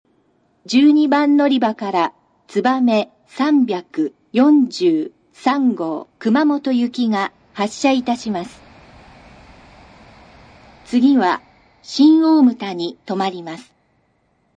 スピーカー：安全柵埋込型（新幹線）　TOA丸型（在来線）
発車放送 つばめ343号・熊本　(71KB/14秒)
奇数番線は男性、偶数番線が女性の構成です。
どのホームも音量が非常に大きく、完全に音割れしています。